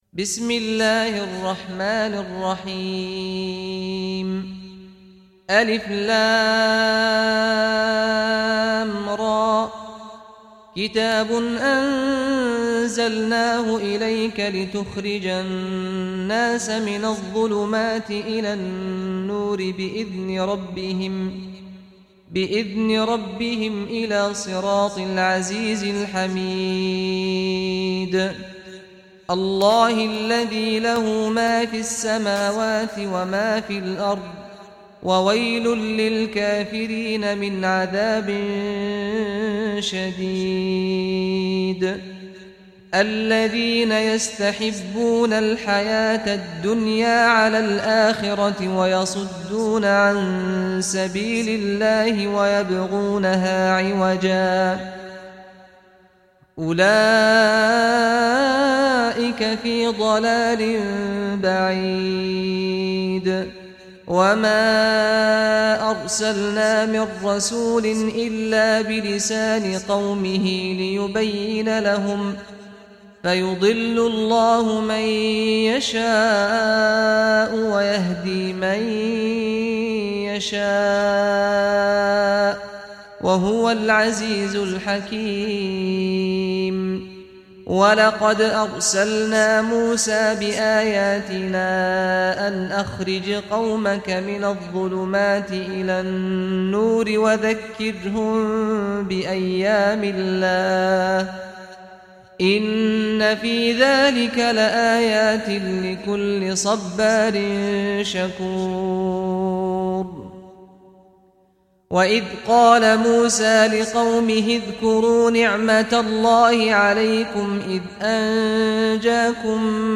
Surah Ibrahim Recitation by Sheikh Saad al Ghamdi
Surah Ibrahim, listen or play online mp3 tilawat / recitation in Arabic in the beautiful voice of Imam Sheikh Saad Al Ghamdi.